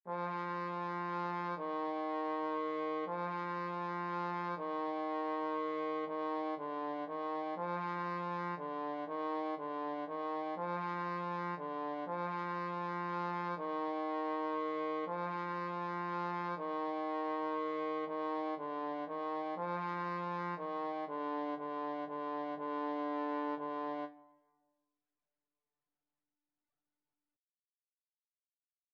3/4 (View more 3/4 Music)
D4-F4
Trombone  (View more Beginners Trombone Music)
Classical (View more Classical Trombone Music)